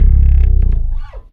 10BASS01  -L.wav